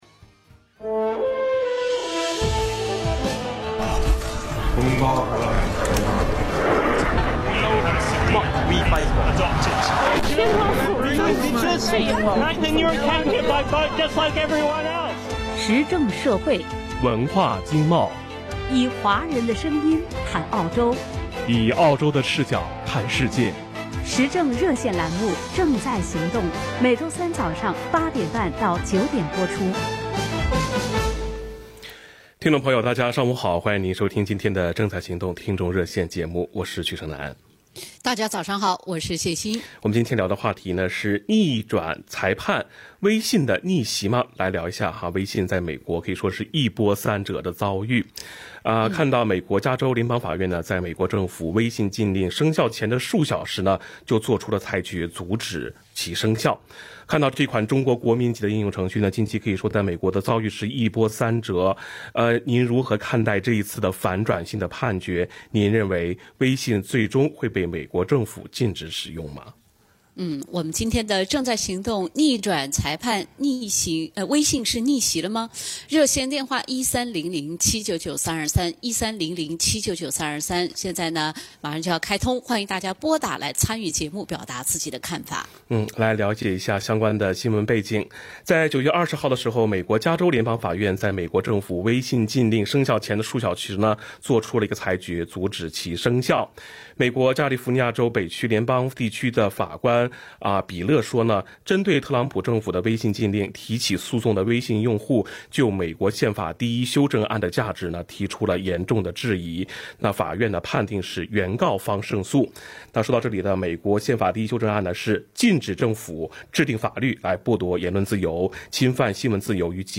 听众热线：特朗普反微信反的到底是什么？